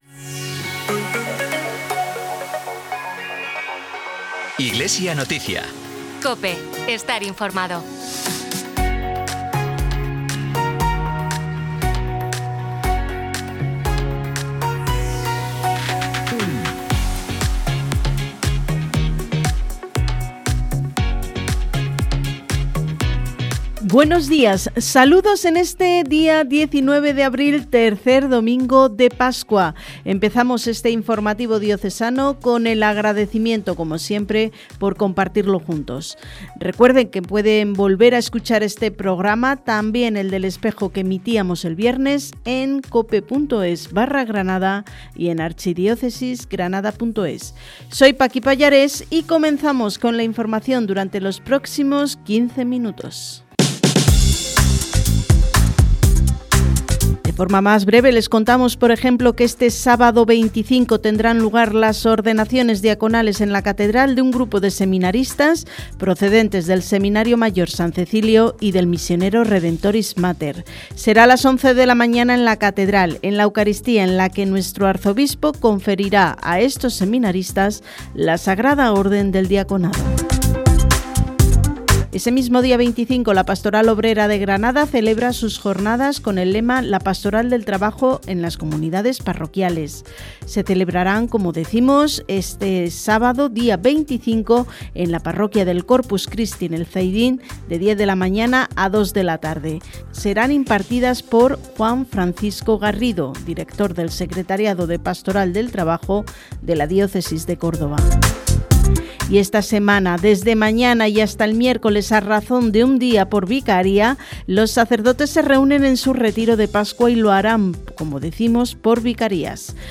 Programa emitido en COPE Granada y COPE Motril el 19 de abril de 2026.